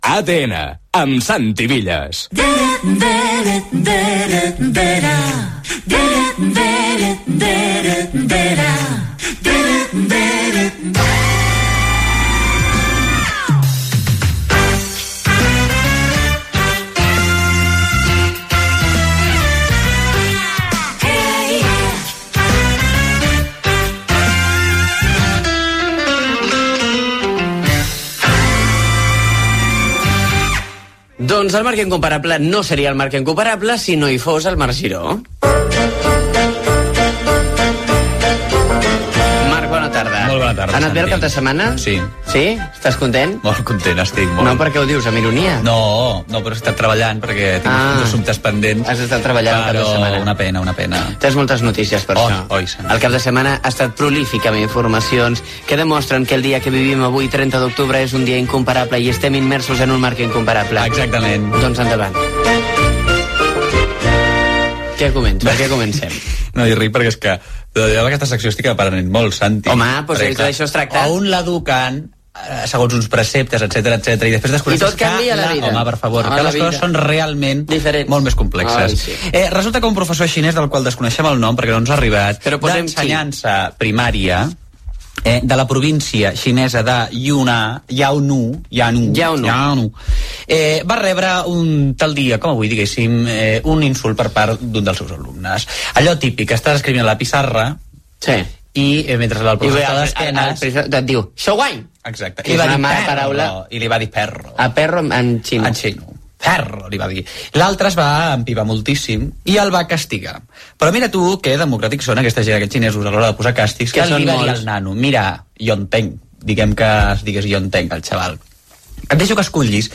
Indicatiu del programa, "el dia que vivim" amb informacions curioses, les llegedes urbanes Gènere radiofònic Entreteniment